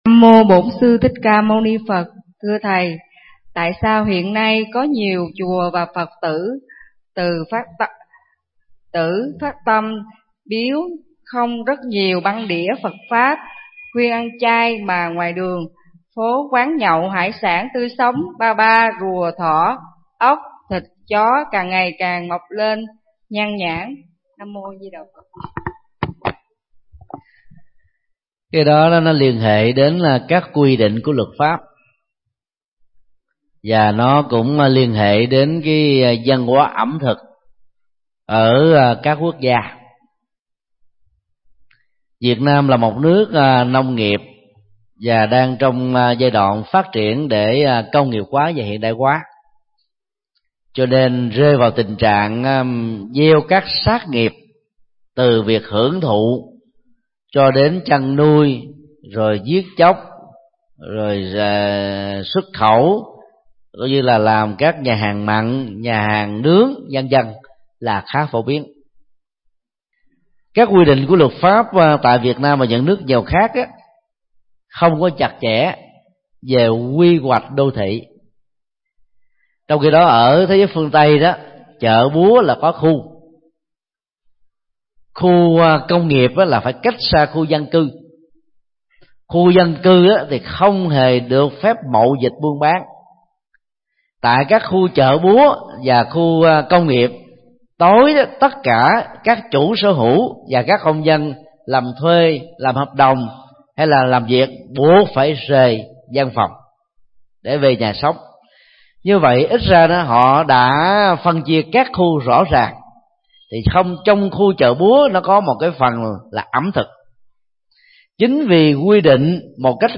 Vấn đáp: Văn hóa ẩm thực chay và mặn – Thượng Tọa Thích Nhật Từ